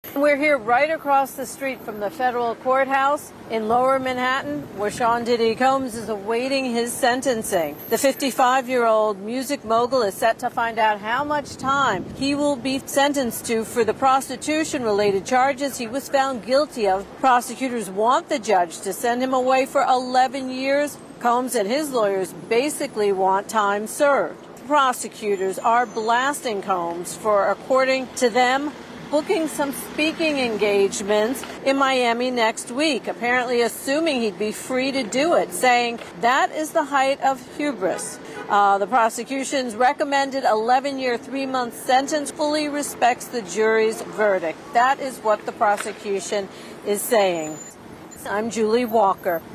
reports from New York as a sentencing hearing continues for Sean "Diddy" Combs.